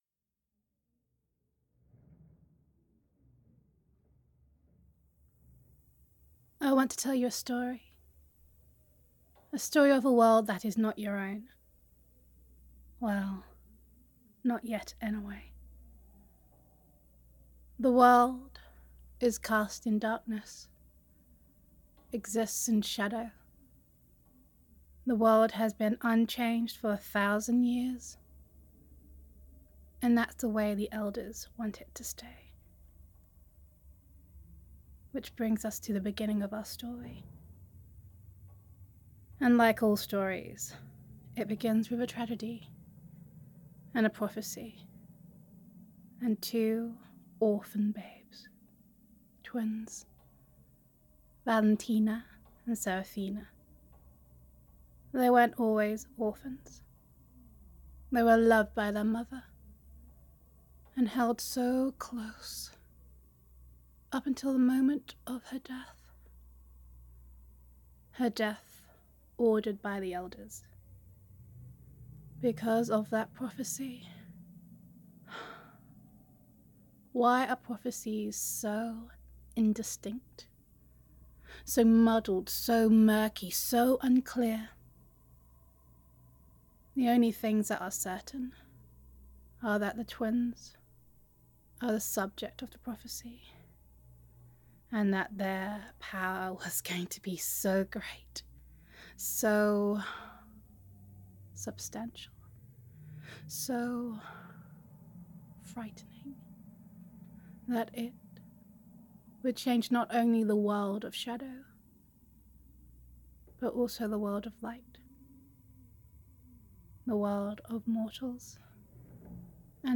[F4A] Light & Shadow - Series Teaser [Urban Fantasy][Exposition for the Win][Werewolves and Vampires and a Dark Prophecy, Oh My!]